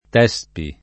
t$Spi] pers. m. stor. — raro Tespide [